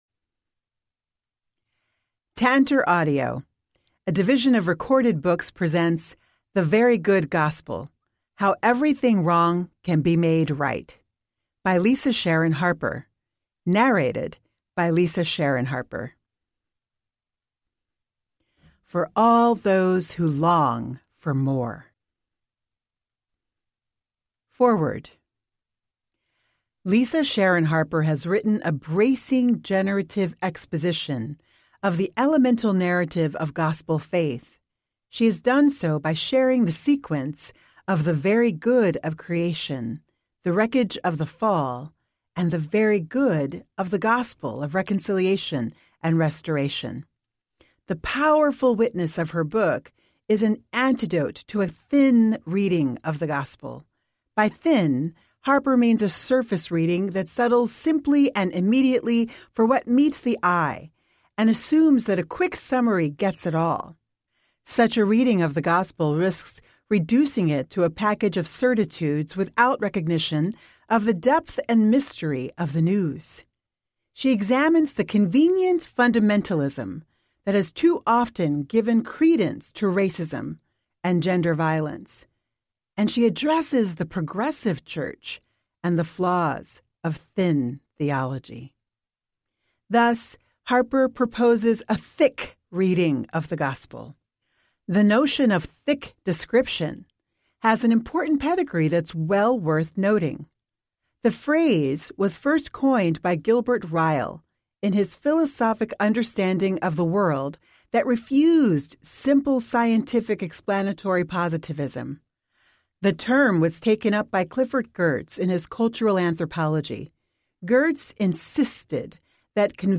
The Very Good Gospel Audiobook